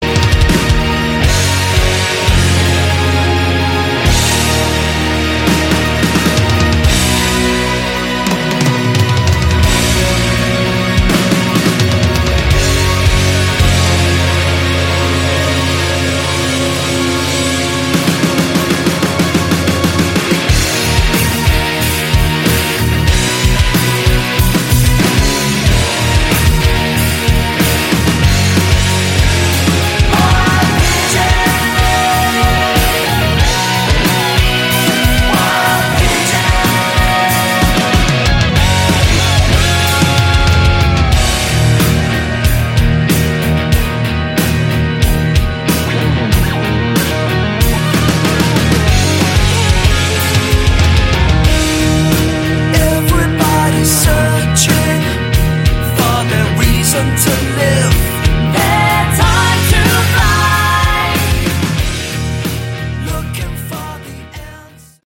Category: Rock
vocals, guitar
drums, vocals
bass